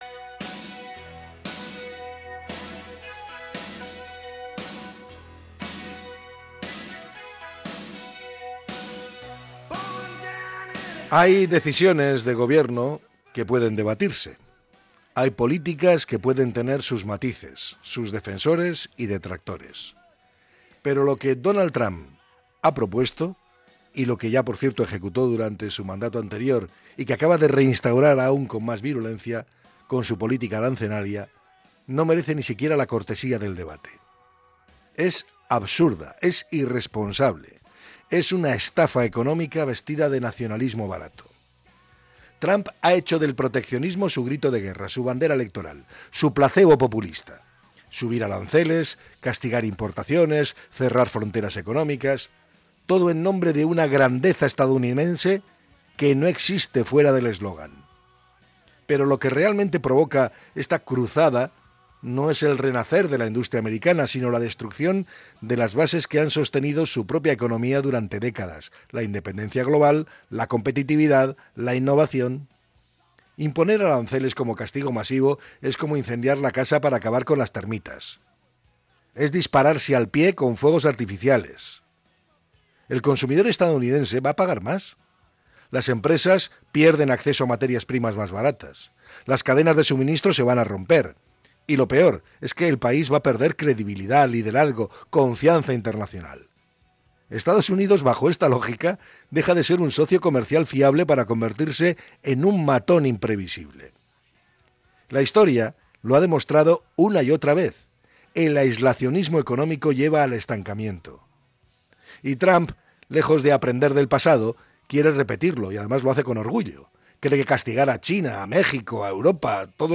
Podcast Opinión